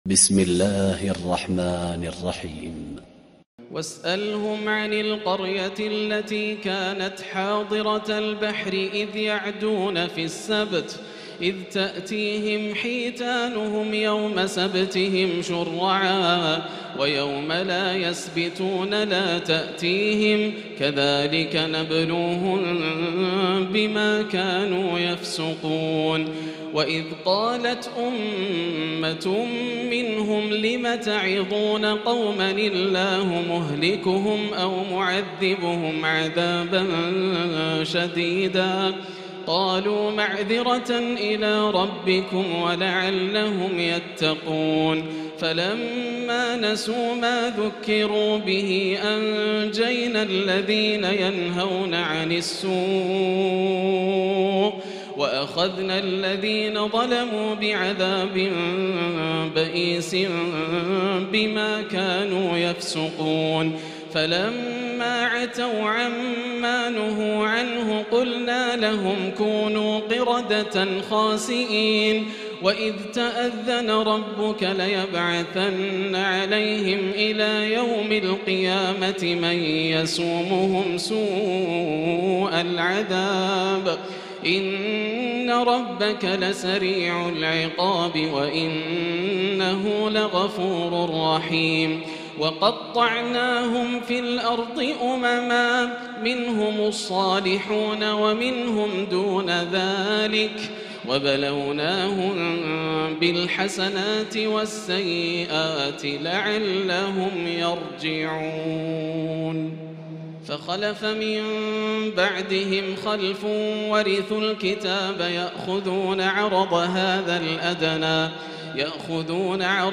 الليلة الثامنة - ما تيسر من سورة "الأعراف" 163 حتى سورة "الأنفال" 40 > الليالي الكاملة > رمضان 1438هـ > التراويح - تلاوات ياسر الدوسري